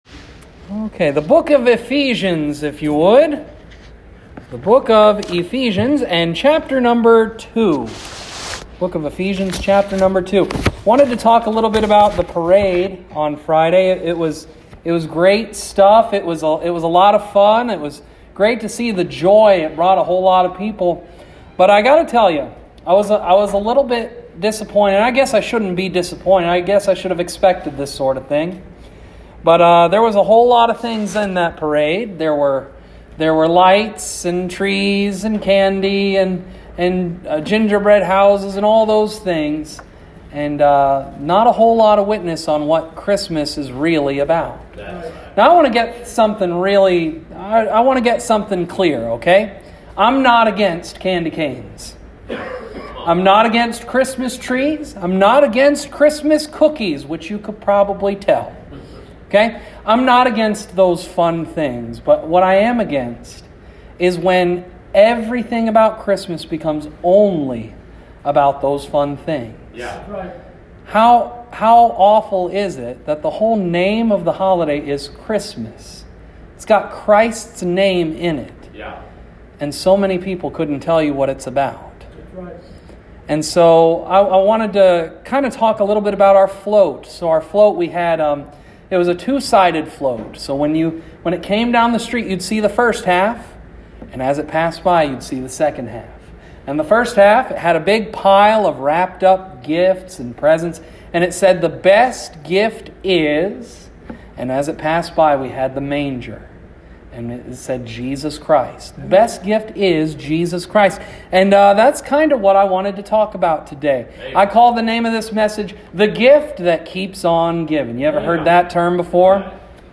The Gift That Keeps On Giving (Sunday AM)